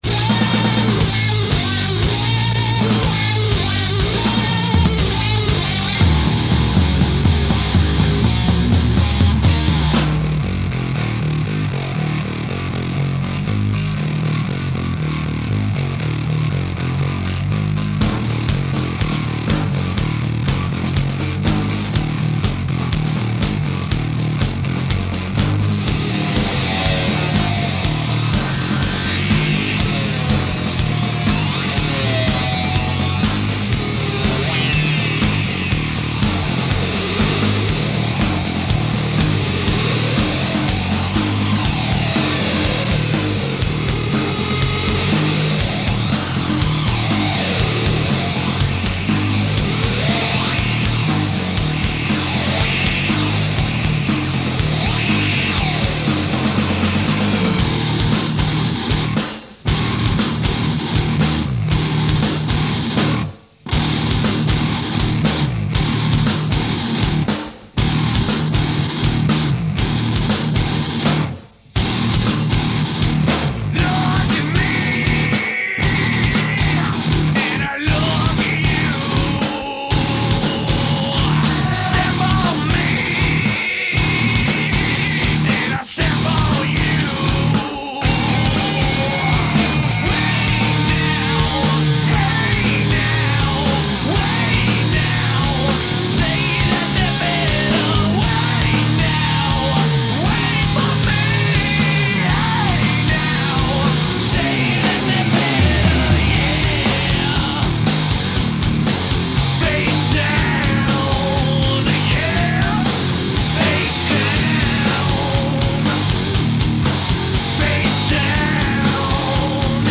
2.4 megabytes - mono